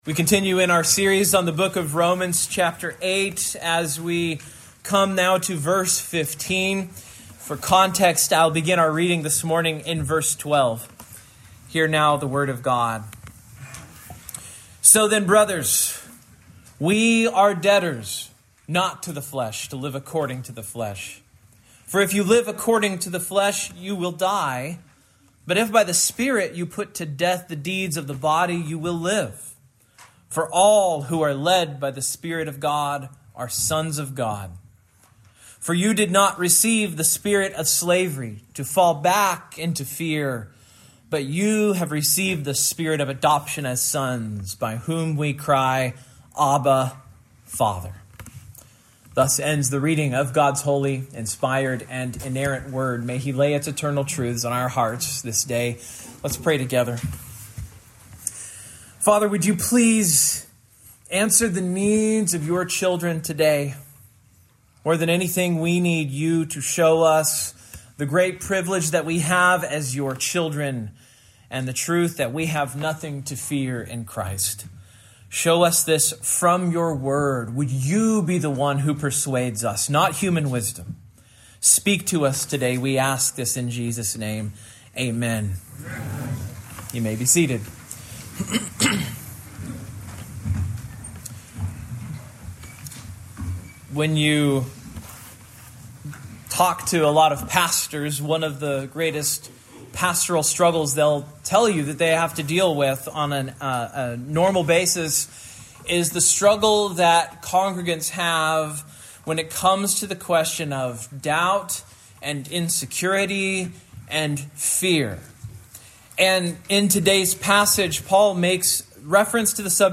Service Type: Morning